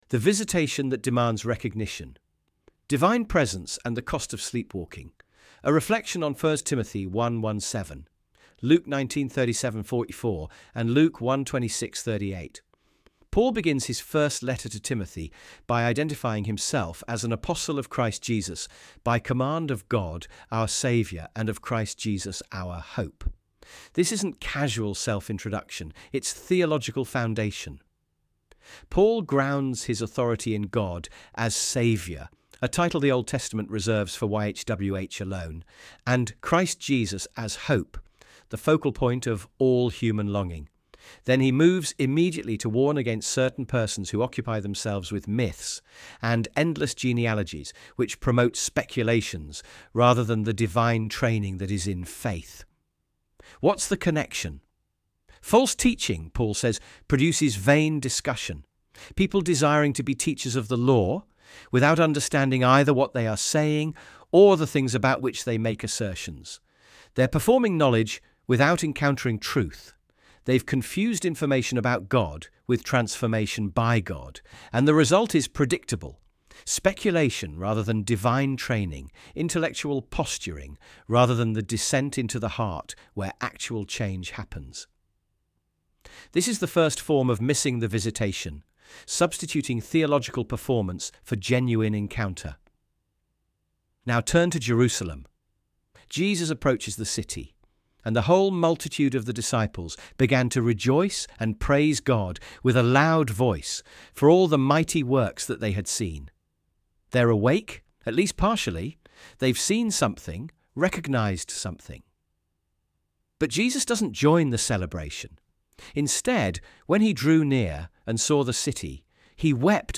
audio.texttospeech-16.mp3